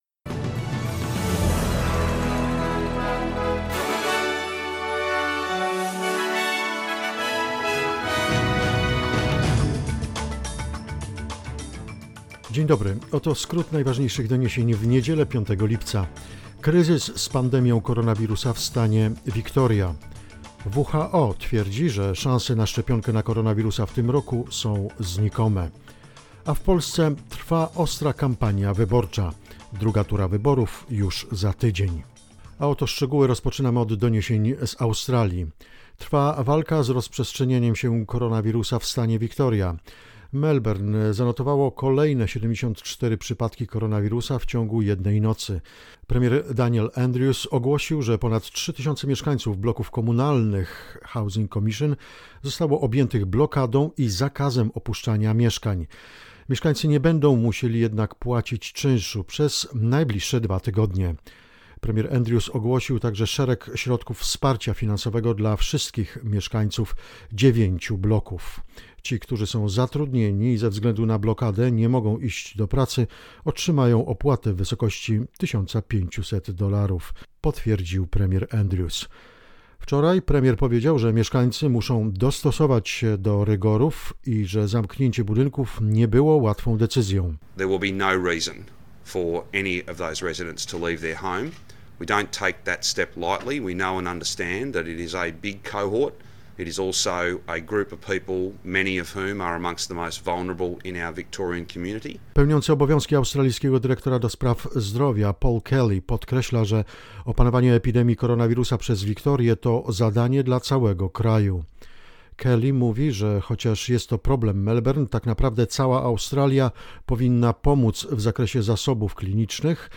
SBS News 5 July 2020